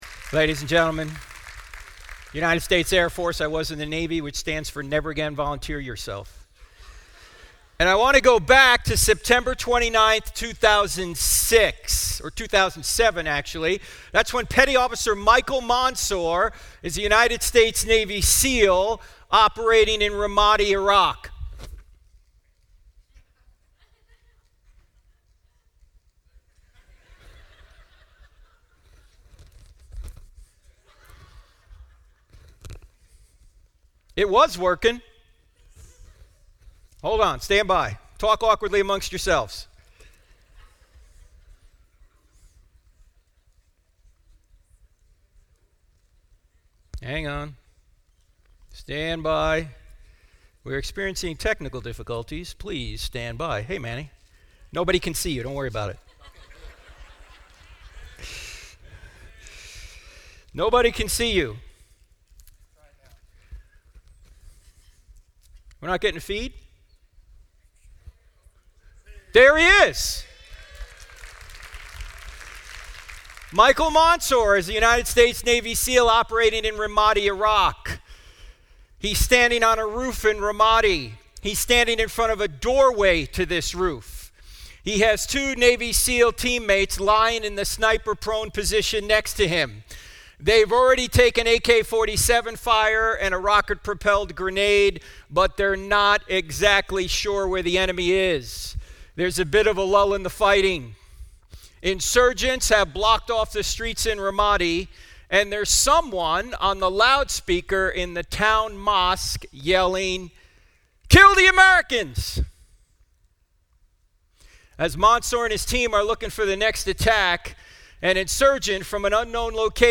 Insight Is 2020: Apologetics Conference